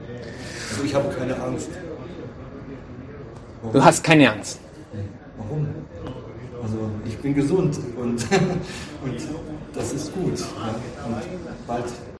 La charla se produce a dos metros de distancia, que es el espacio que los facultativos le han pedido que respete, a pesar de que está sano y no tiene ningún síntoma.
"Estoy bien, estoy tranquilo, no tengo el virus", nos dice en un tono relajado con una voz que escuchamos con la puerta de la calle ligeramente entreabierta.